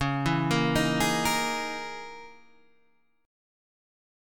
C# Minor 6th Add 9th